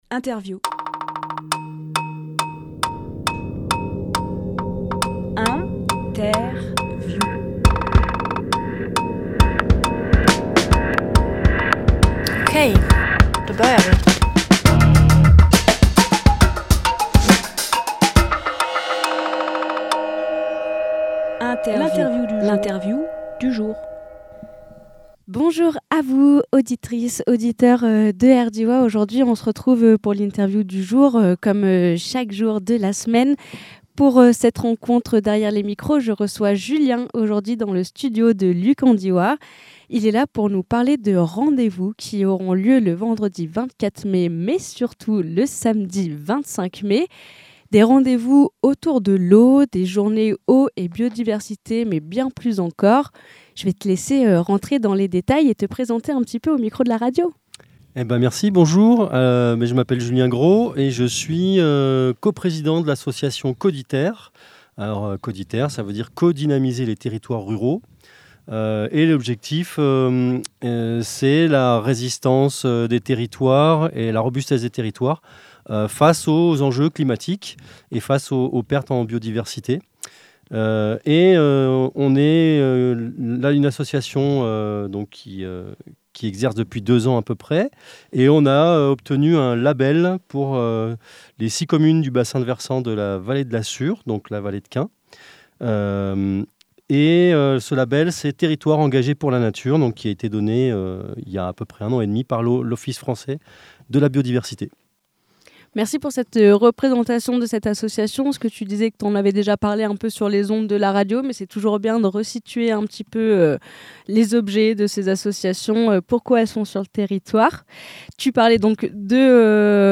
Emission - Interview Les Rendez-vous Autour de l’Eau Publié le 21 mai 2024 Partager sur…